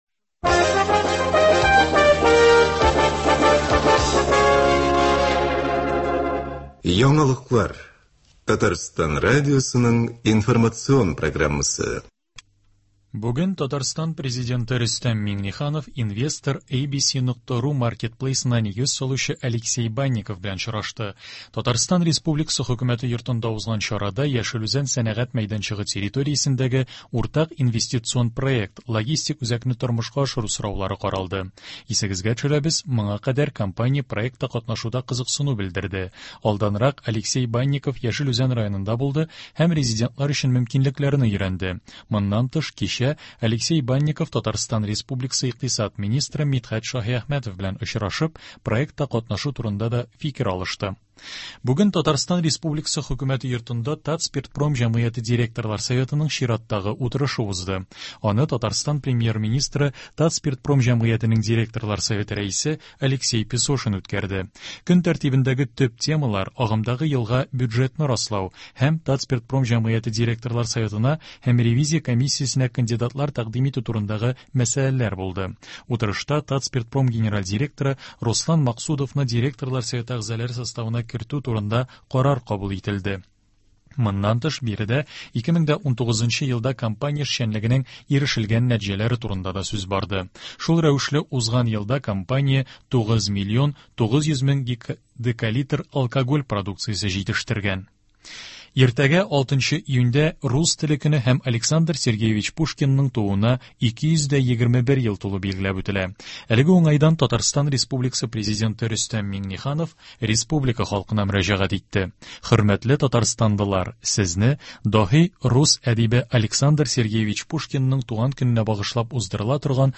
Яңалыклар. 5 июнь.